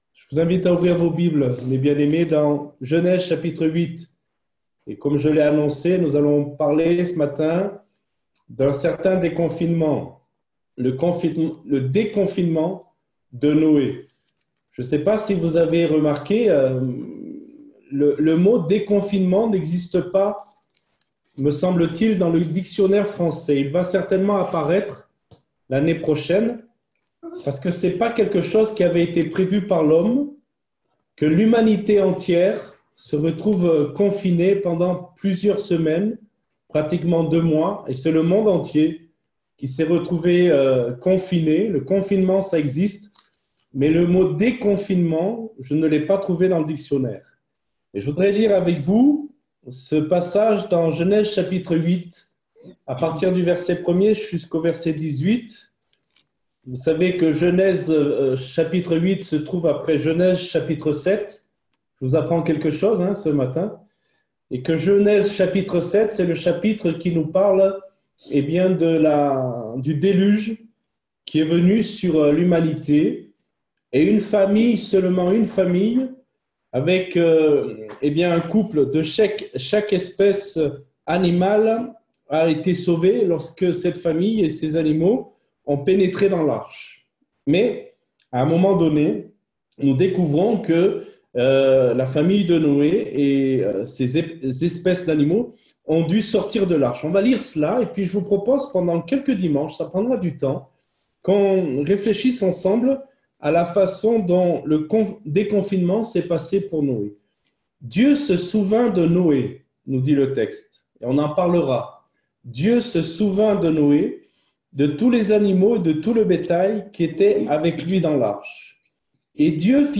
Voici la prédication de ce matin